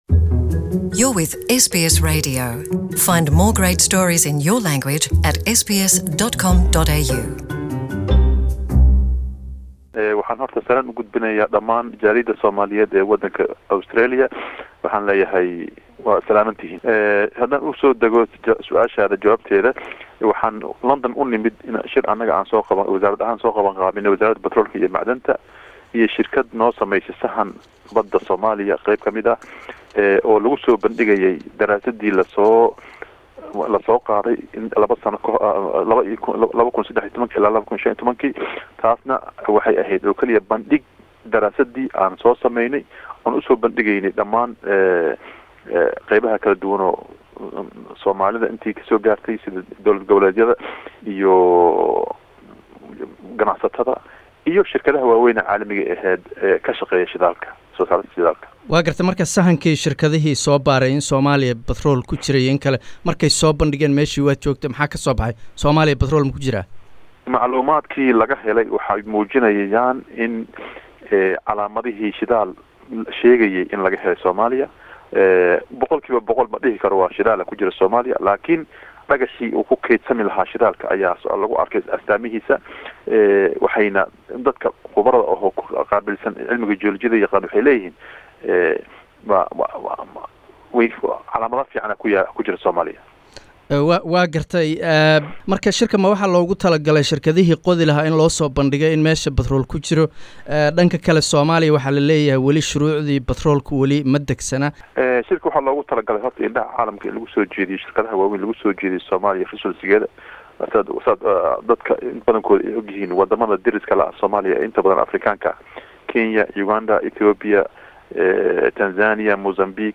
Interview: Somali minister of petroleum and mineral resources, Abdirashid Mohamed Ahmed